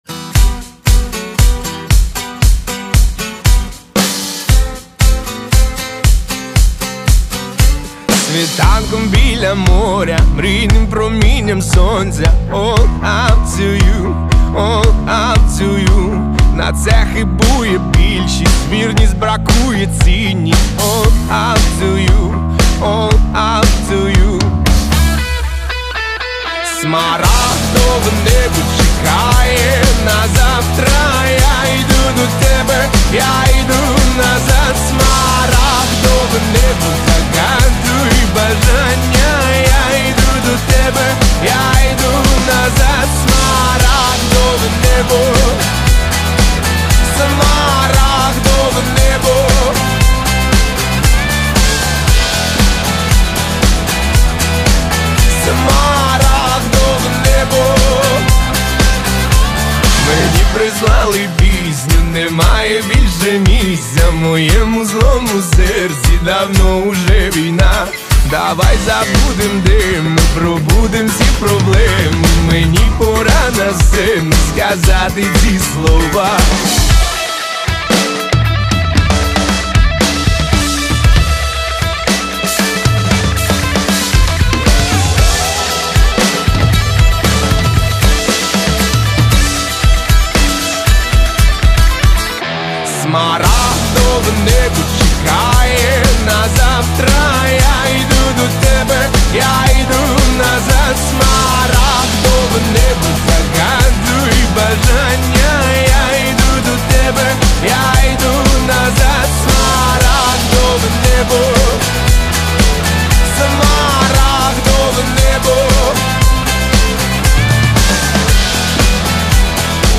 Всі мінусовки жанру Pop-Rock
Плюсовий запис